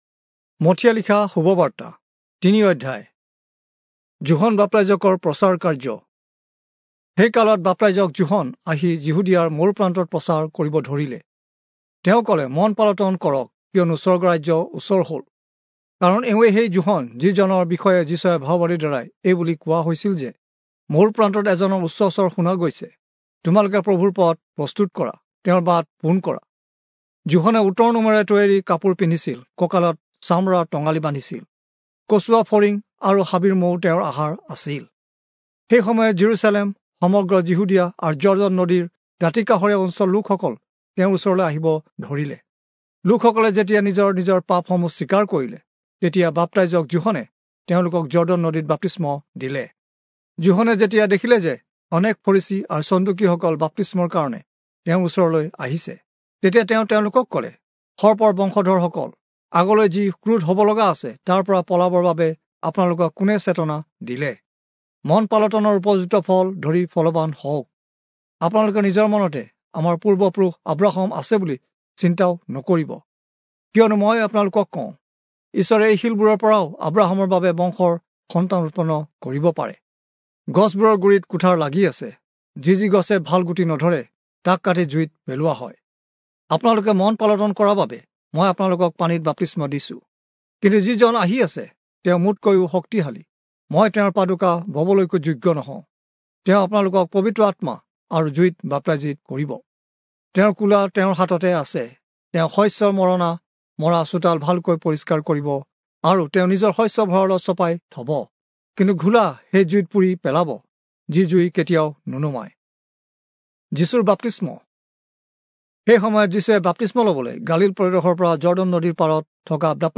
Assamese Audio Bible - Matthew 27 in Ecta bible version